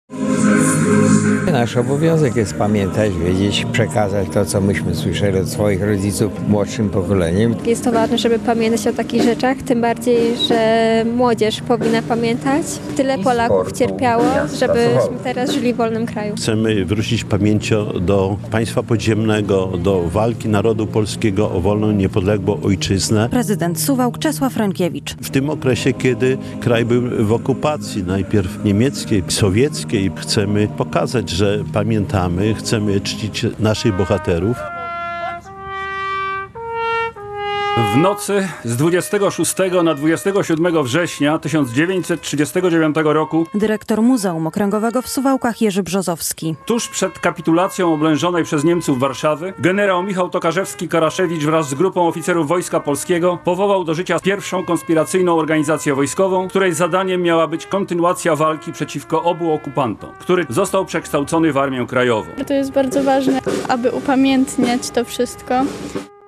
Mieszkańcy Suwałk, samorządowcy i przedstawiciele miejskich instytucji, szkół, wzięli udział w uroczystościach z okazji Dnia Polskiego Państwa Podziemnego.